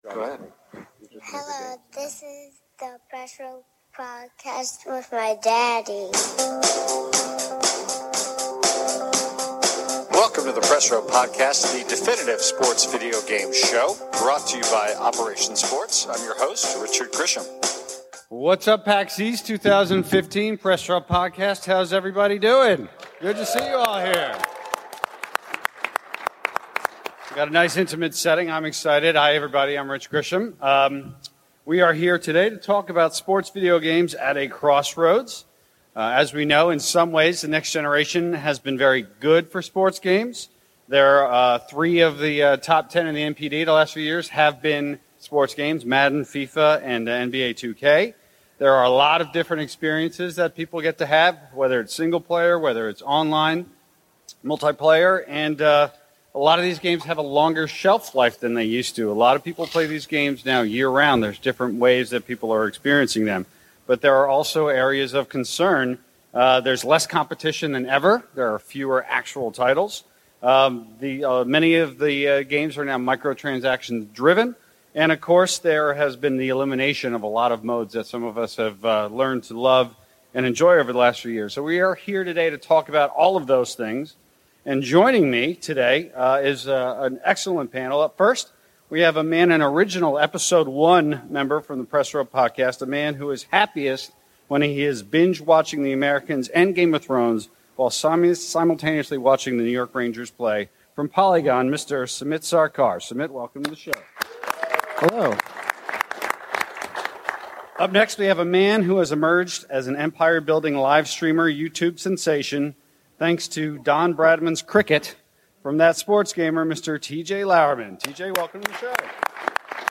PAX East 2015 Panel
For the third straight year, the Press Row Podcast invaded PAX East for a panel on the state of sports games.
These include the continued expansion of Ultimate Team modes and their influence on overall sports game development, whether big-time sports games have gotten too difficult, and how the market could look to expand while continuing to serve its base. It was a spirited session, and we’re happy that we can deliver it to all of the listeners of the show.